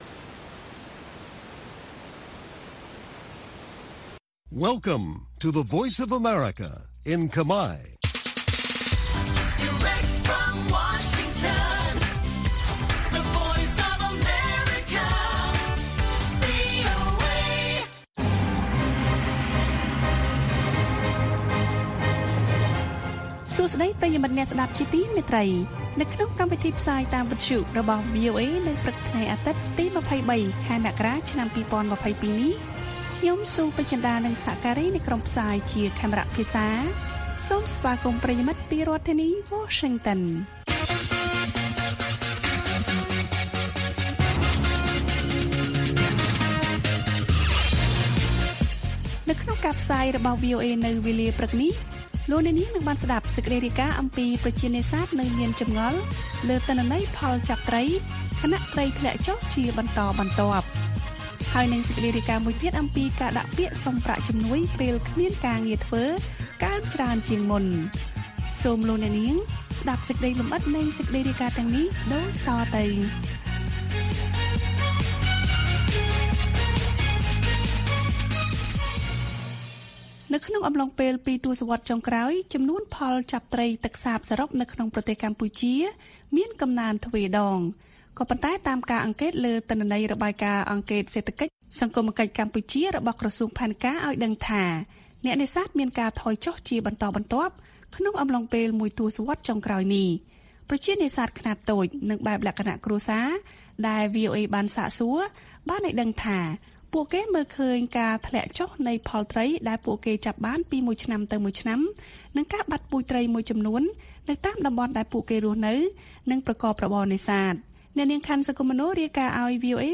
ព័ត៌មានពេលព្រឹក៖ ២៣ មករា ២០២២